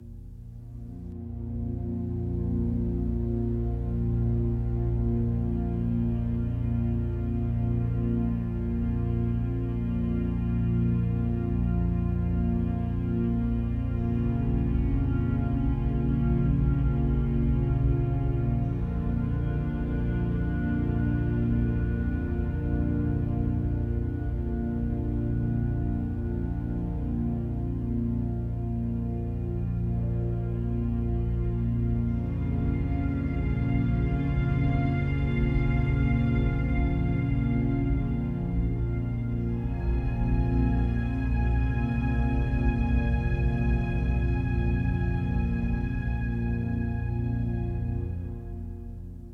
01 - Ambience